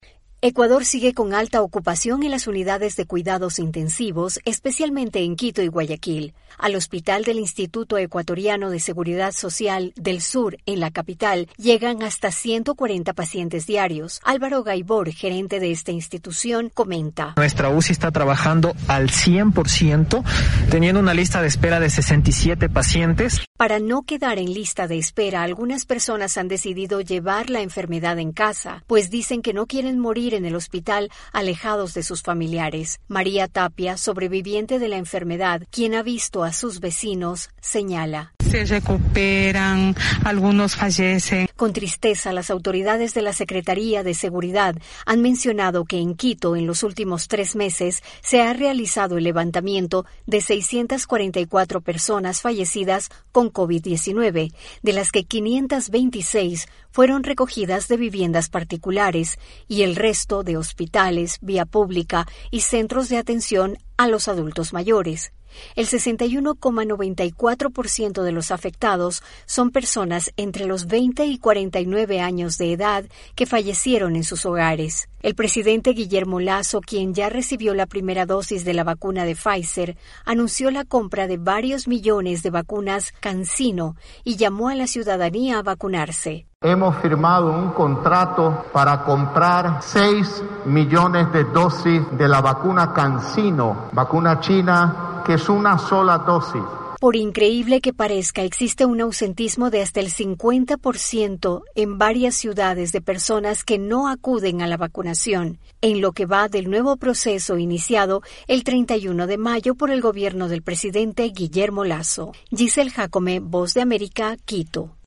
AudioNoticias